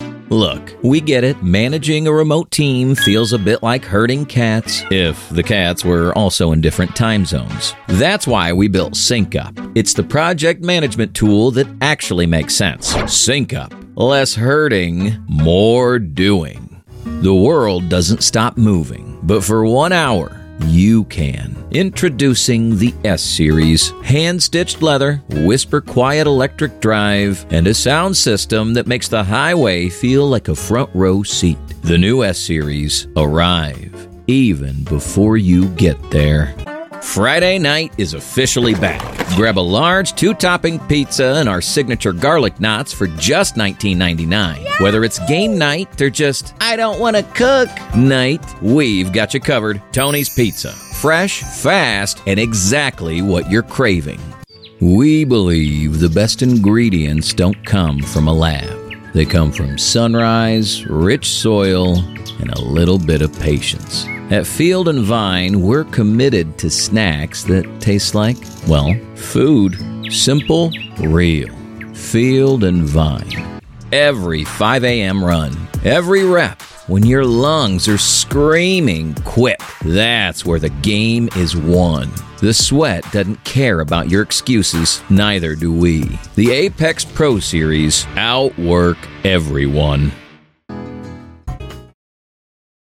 Full Commercial Demo - 2026
Neutral North American / General American, Midwest (Native)/ Great Lakes, Southern (US), New York / Brooklyn, Gritty / Blue Collar, Mid-Atlantic / Transatlantic,
Young Adult
Middle Aged